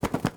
SFX_Duck_Wings_02.wav